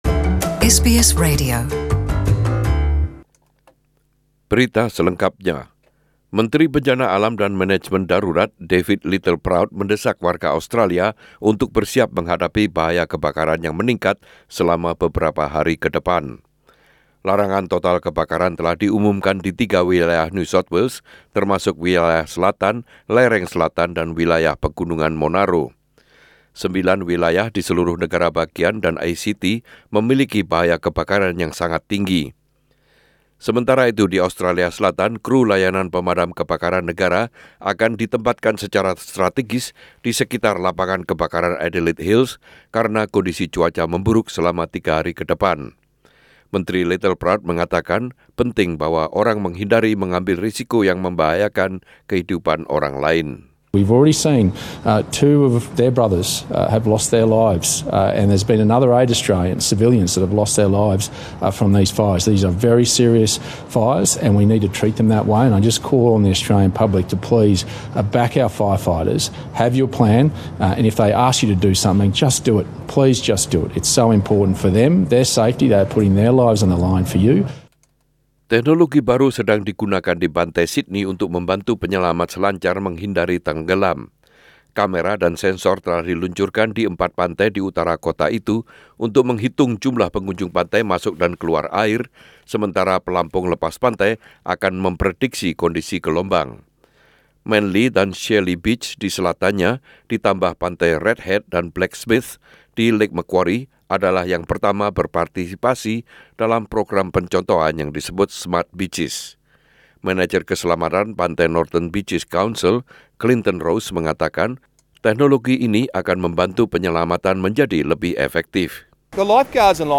SBS Radio News in Indonesian - 29/12/2019